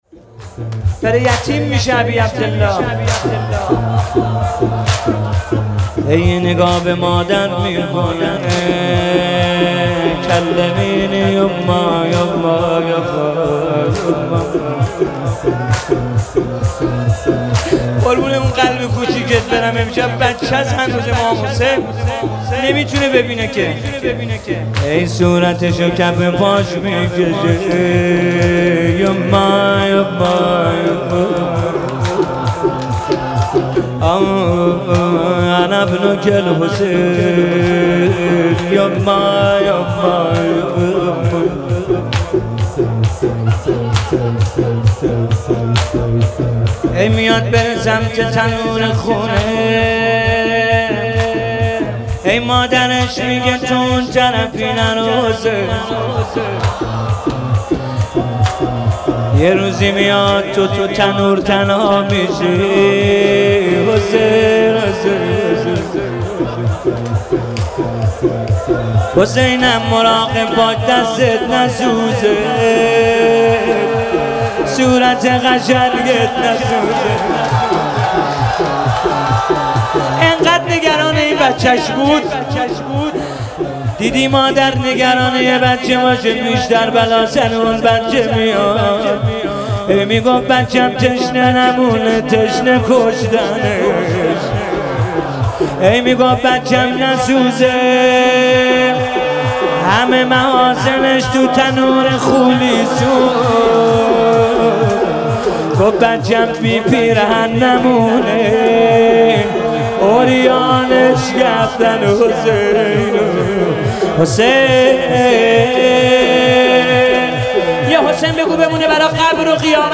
روضه – محتوانشر